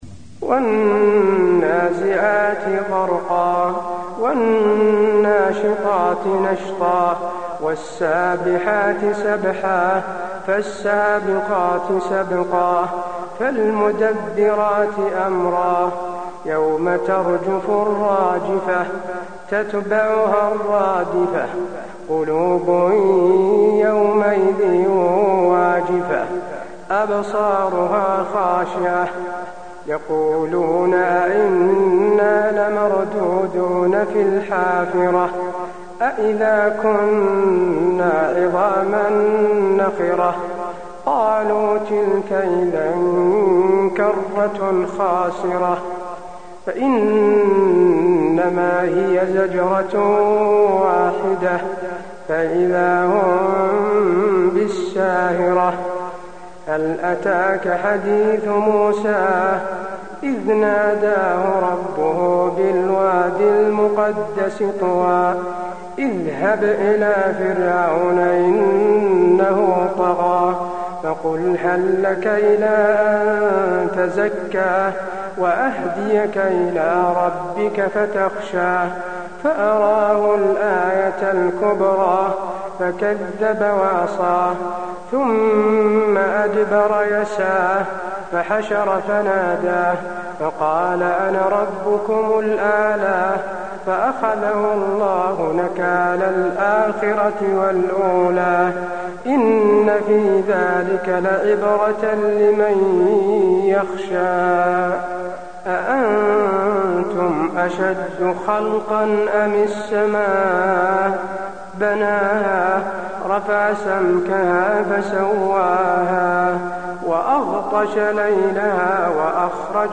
المكان: المسجد النبوي النازعات